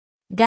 g 32
Phoneme_(Commonscript)_(Accent_0)_(32)_(Female).mp3